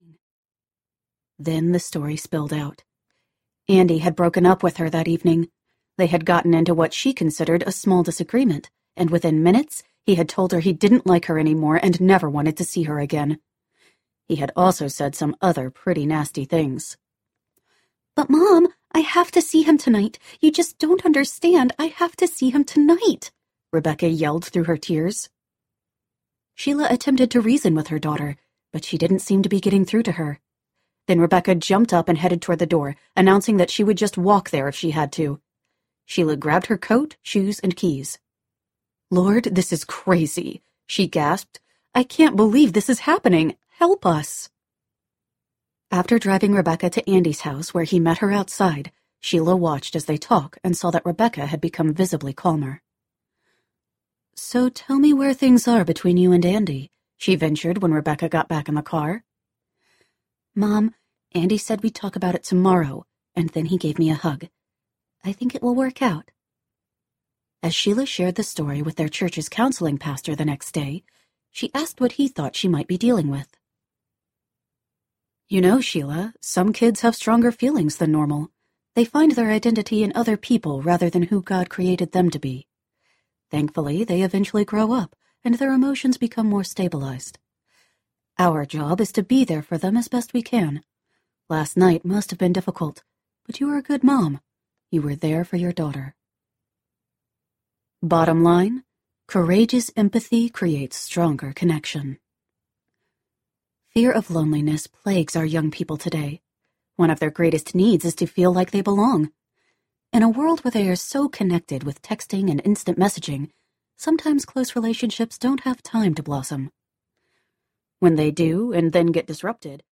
With All Due Respect Audiobook
6.35 – Unabridged